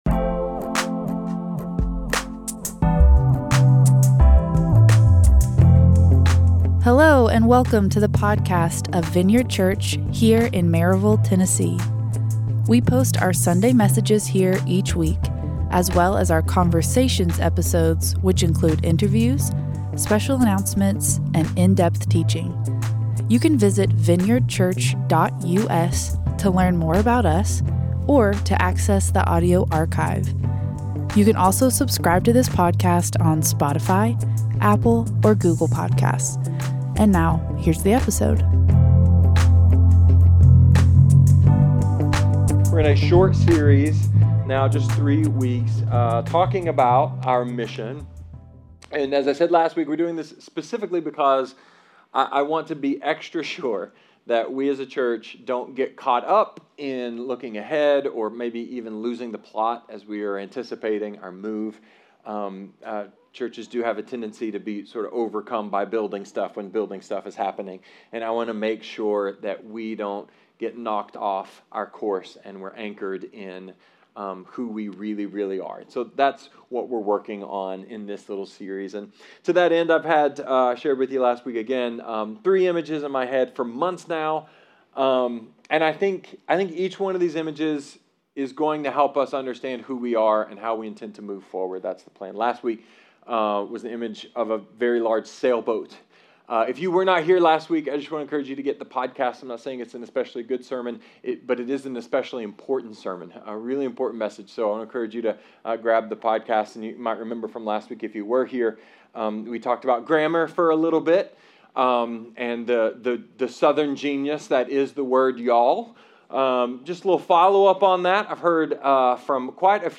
A sermon about trees, micro-climates, interdependence, and renewal.